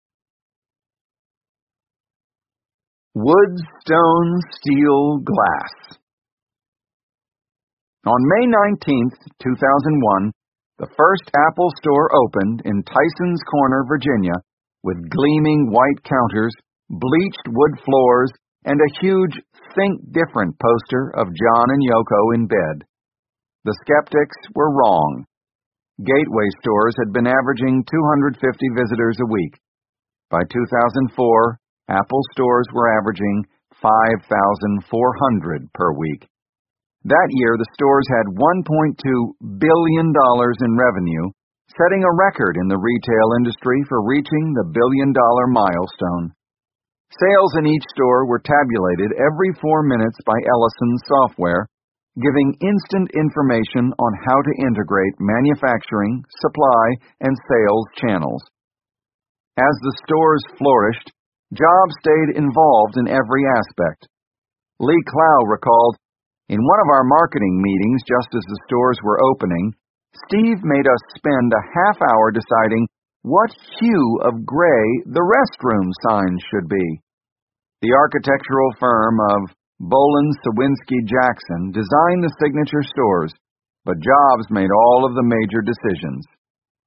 在线英语听力室乔布斯传 第485期:木材 石头 钢铁 玻璃(1)的听力文件下载,《乔布斯传》双语有声读物栏目，通过英语音频MP3和中英双语字幕，来帮助英语学习者提高英语听说能力。
本栏目纯正的英语发音，以及完整的传记内容，详细描述了乔布斯的一生，是学习英语的必备材料。